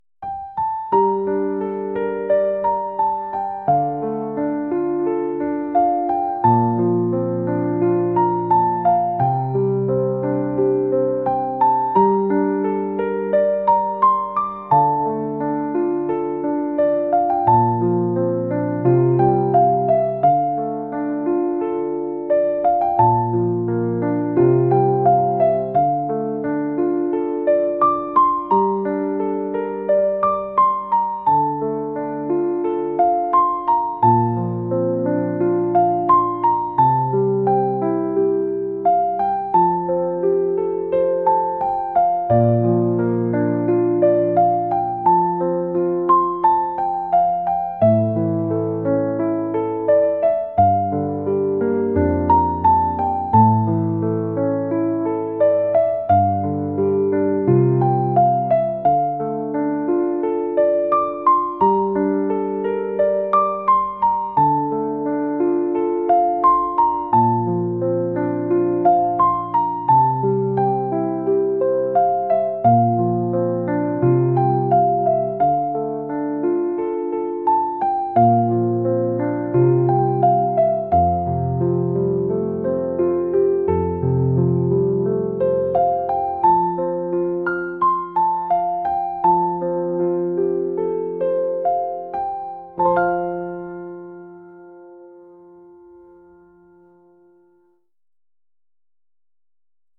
classical | ambient | cinematic